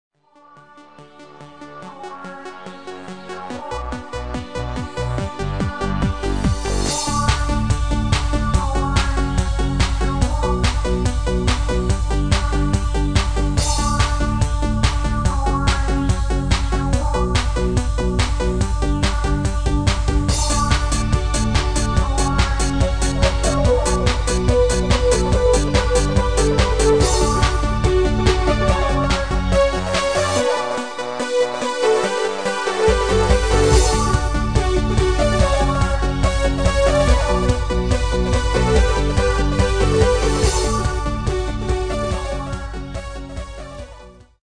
Extended MIDI File Euro 12.00
Demo's zijn eigen opnames van onze digitale arrangementen.